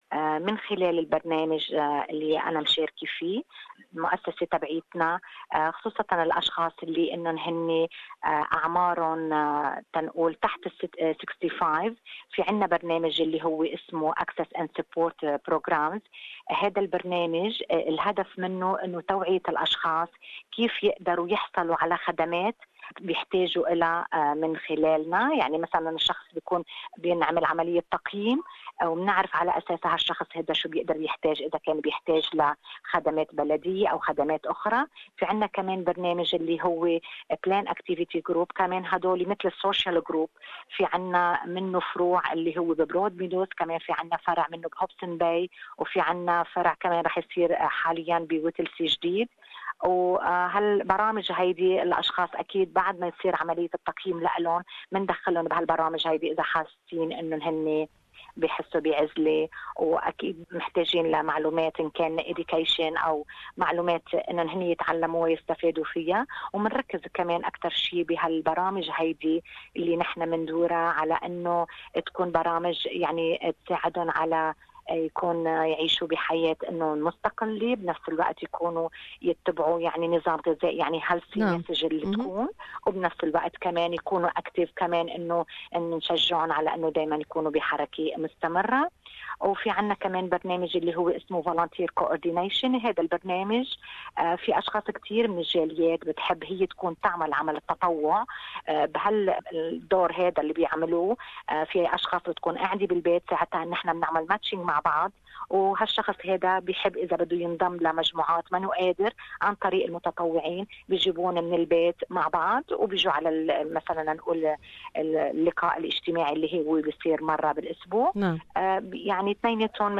Health authorities at this year's Multicultural Health Week are raising awareness about services for carers to ensure language barriers and cultural stigma are not preventing them from seeking assistance. Interview